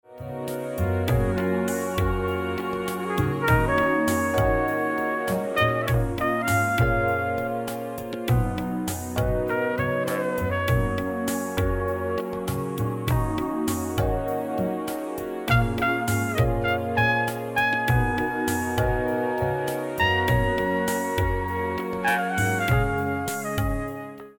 HD-hidden-trumpet.mp3
trumpet.